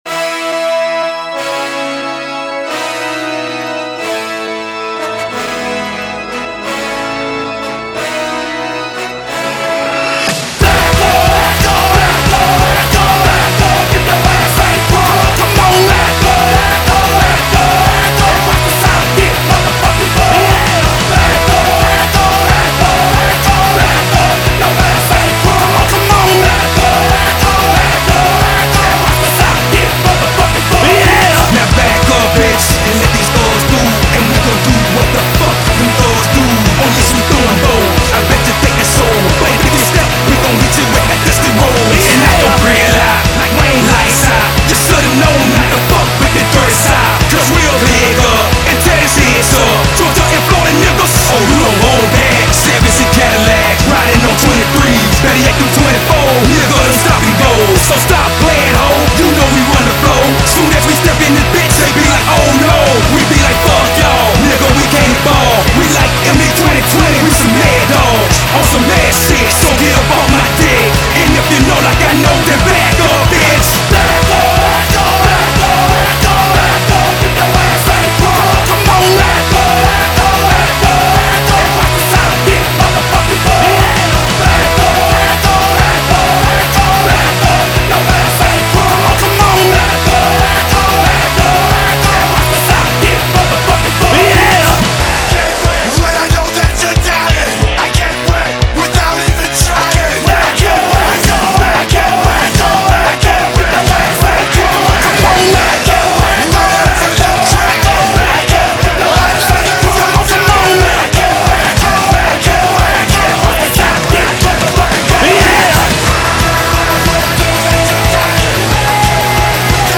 A mashup from the archives. 2007.